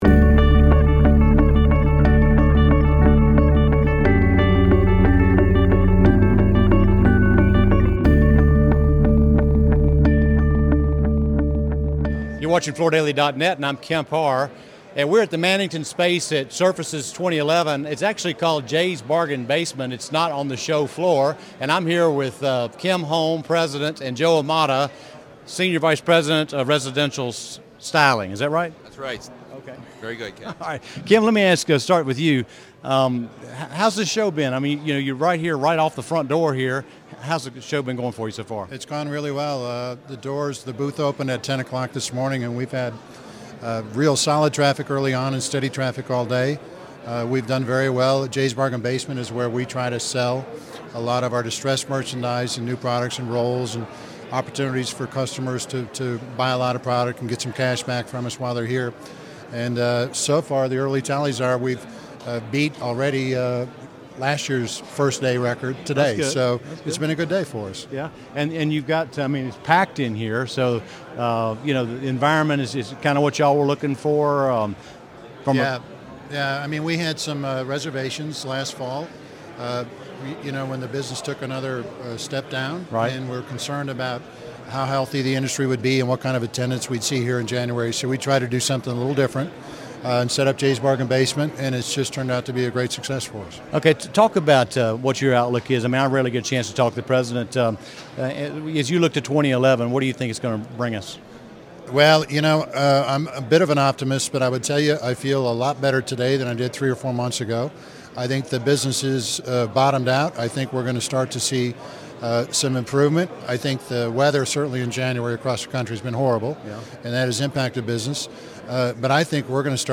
2011—Recorded at Surfaces